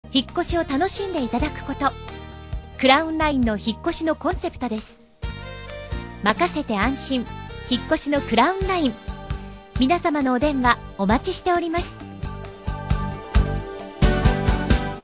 Professionelle japanische Sprecherin für TV / Rundfunk / Industrie.
Kein Dialekt
Sprechprobe: Industrie (Muttersprache):